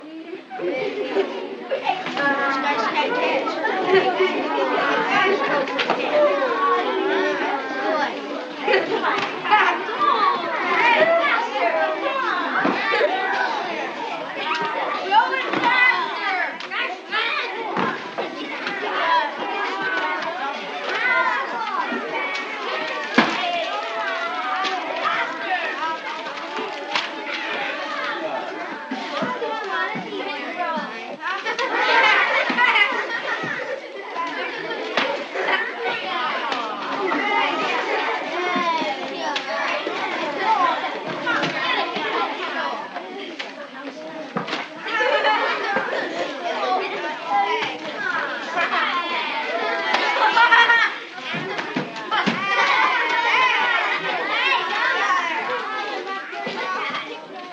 Звуки первого сентября в школе для монтажа видео слушать и скачать бесплатно в mp3 формате.
4. Школьники первоклассники